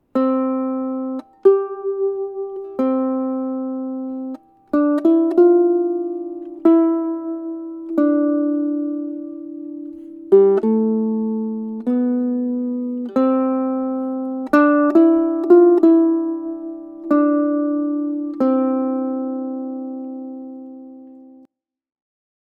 Quarter Note Triplet
Plaisir d'amour features a new rhythm, the quarter note triplet, indicated with a bracket across three quarter notes.
Three Against Two | The quarter note triplet requires playing three quarter notes evenly across two beats.
quater note triplet example